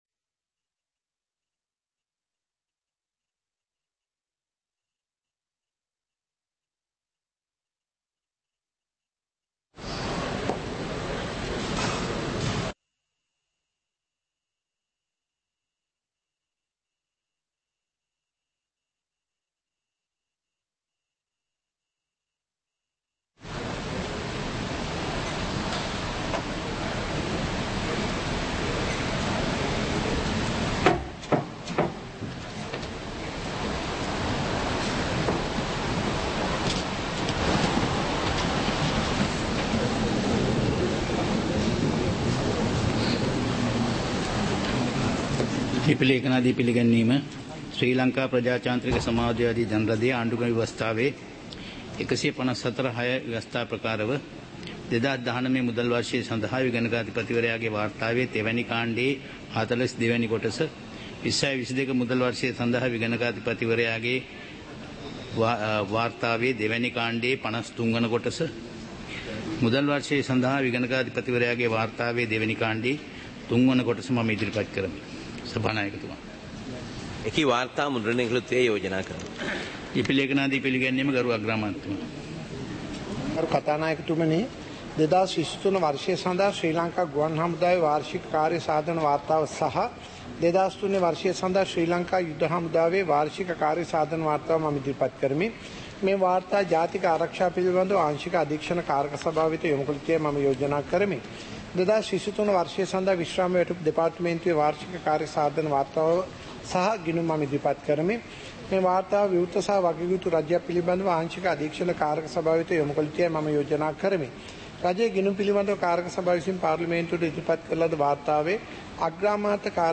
சபை நடவடிக்கைமுறை (2024-08-07)
பாராளுமன்ற நடப்பு - பதிவுருத்தப்பட்ட